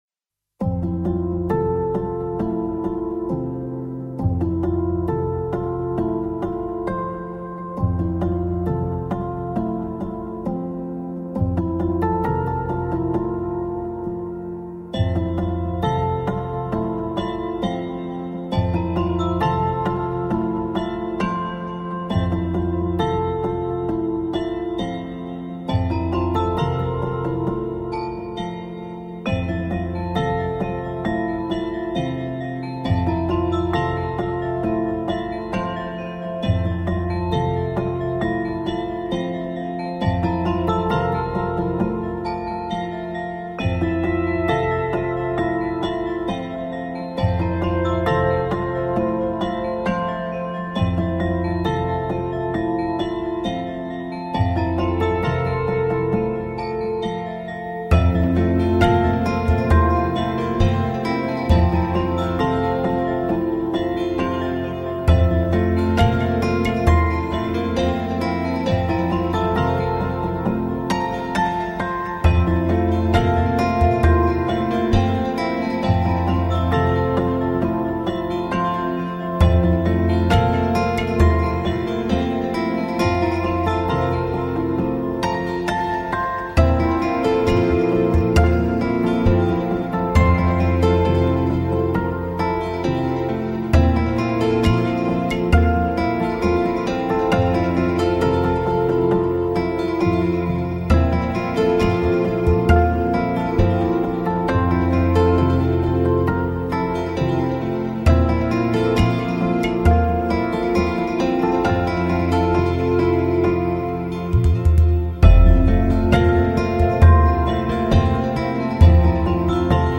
さっそく聴いてみると……いつにもまして、音の粒子がやわらかいような気がする。
随著音乐的自然摇摆，轻荡到世界的摇篮中...。